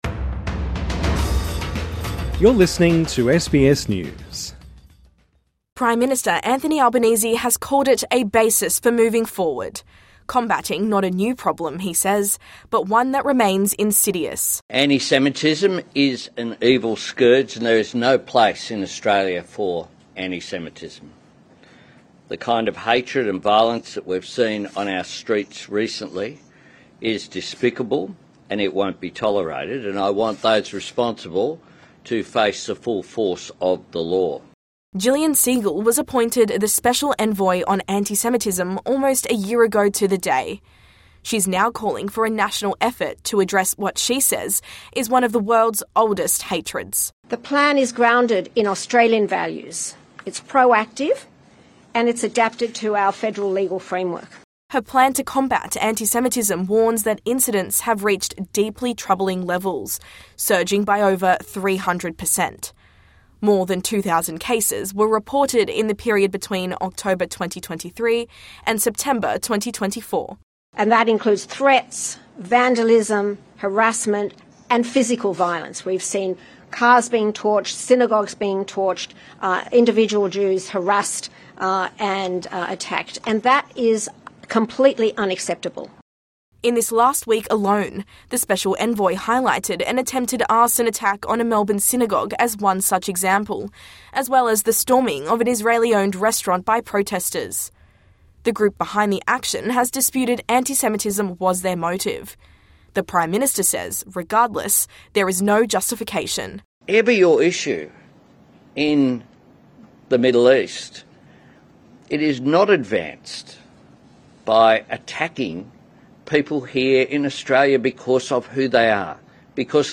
Prime Minister Anthony Albanese and Special Envoy to Combat Antisemitism Jillian Segal speak to media Source